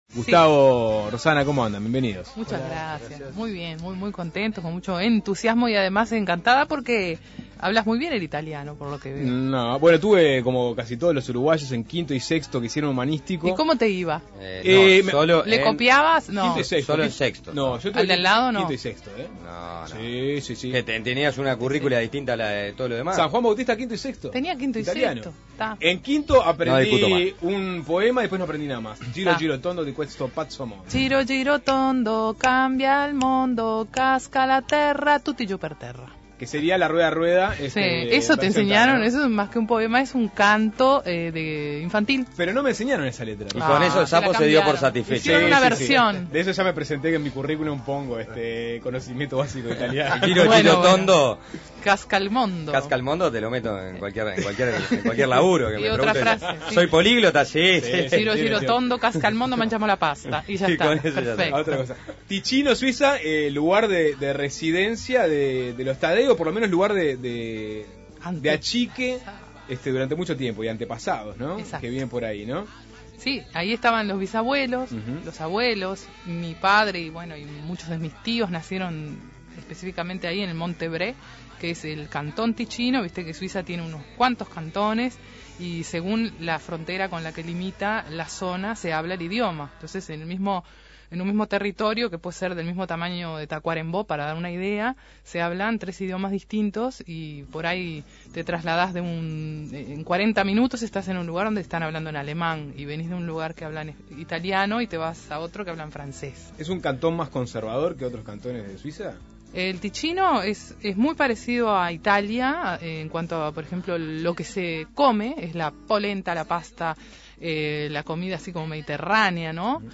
percusionista